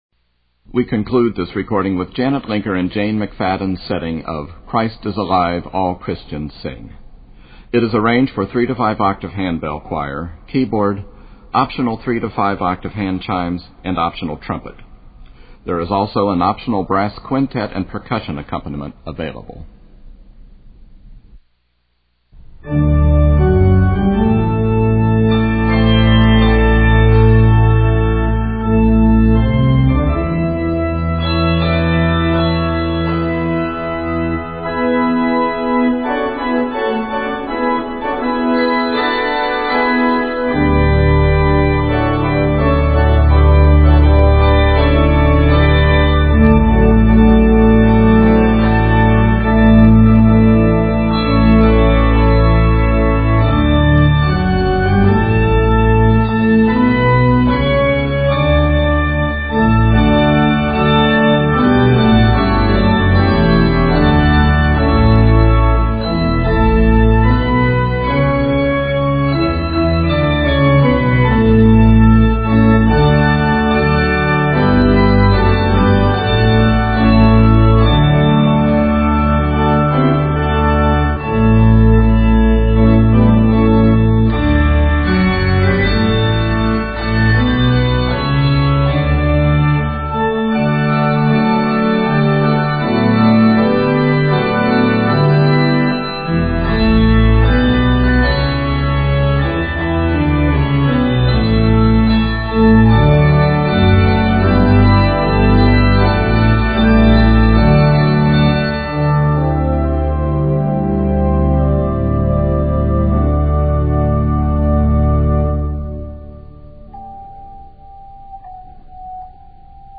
handbells and keyboard